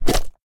slime_attack2.ogg